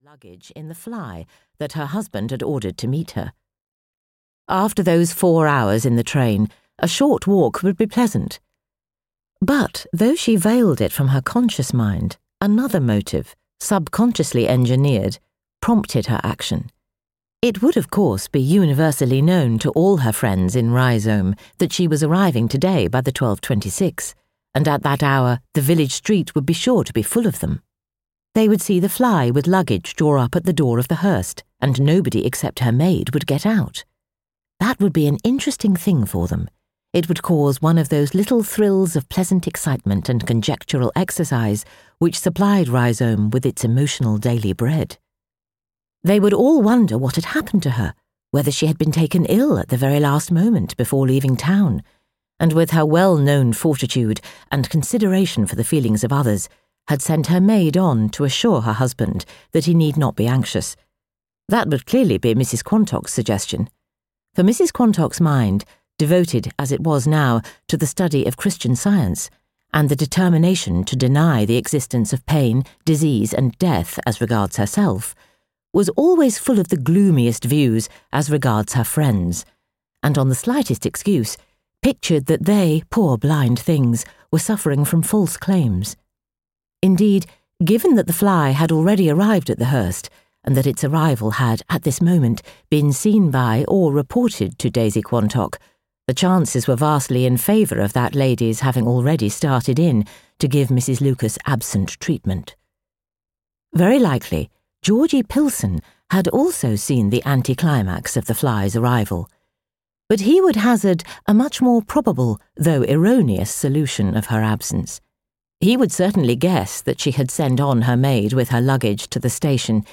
Audio knihaThe Complete Mapp and Lucia, Volume 1 (EN)
Ukázka z knihy